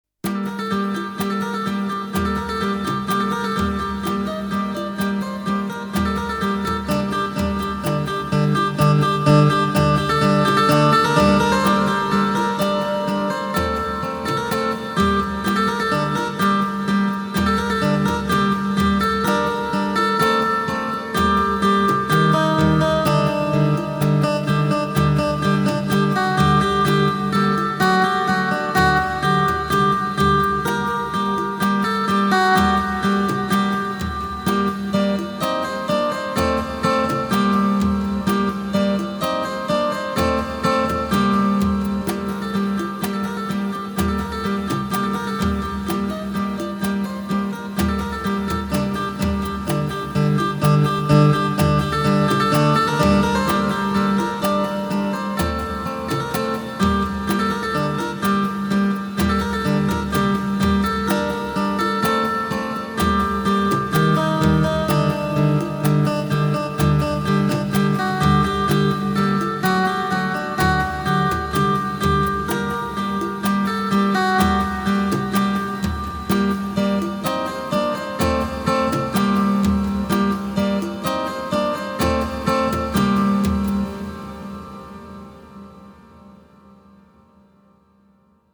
勇敢・試練